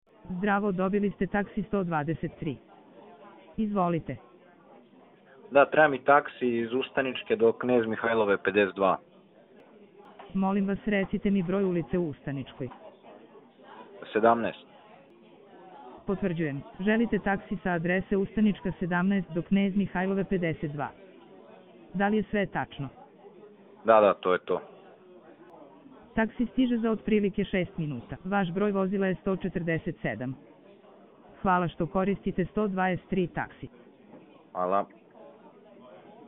Ovo su demonstracije stvarnih scenarija u kojima AI agent razgovara kao pravi operater.